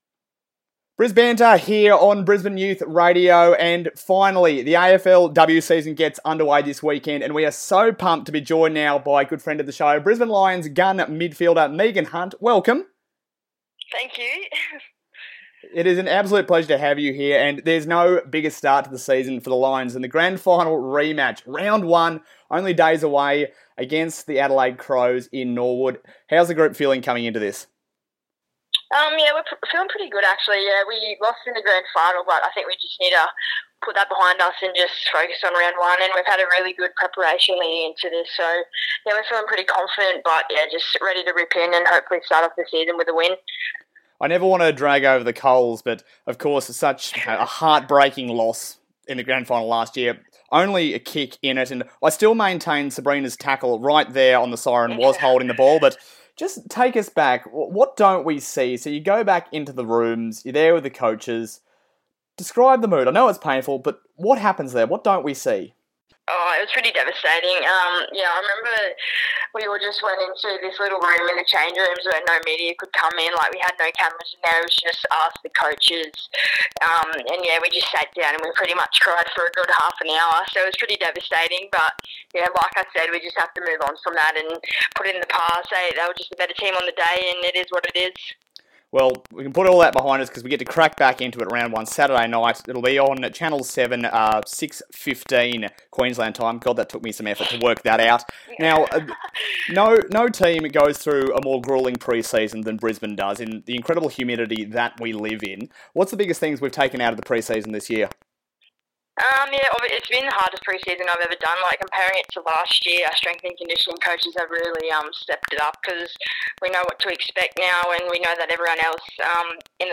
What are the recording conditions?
joins Brisbane Youth Radio to chat about last season and what to expect from the Women's team in 2018.